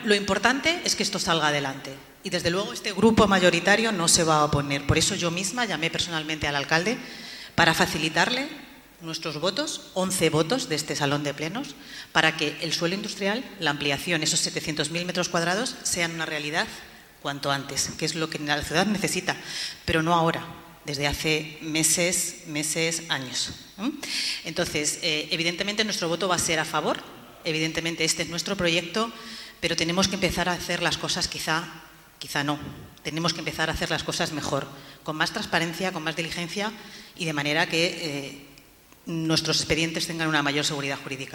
El Grupo Municipal Socialista ha votado a favor de la ampliación del suelo industrial en la ciudad de Toledo que permitirá aumentar el polígono actual en casi 700.000 metros cuadrados. La portavoz, Noelia de la Cruz, ha lamentado que se haya tenido que hacer en un pleno extraordinario y urgente cuando el proyecto se inició en 2021 y cuando el alcalde ya había anunciado que estaría listo antes de finalizar 2024.